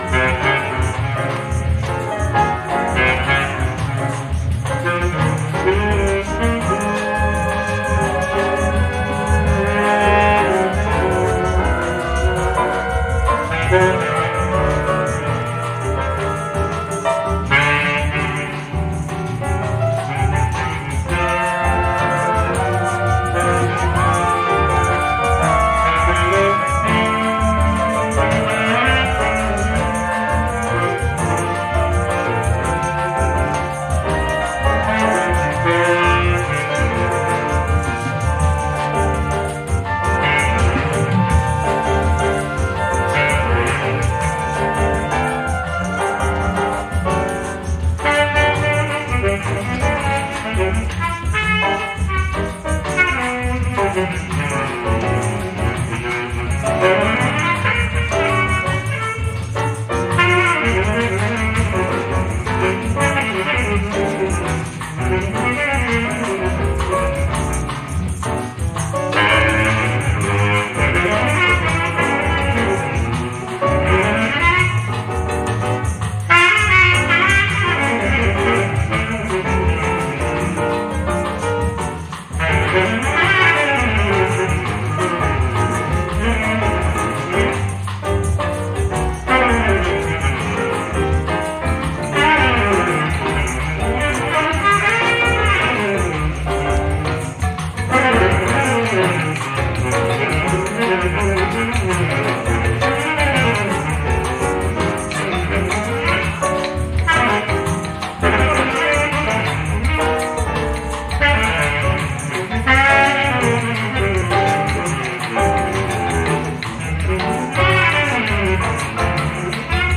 Holy Grail jazz release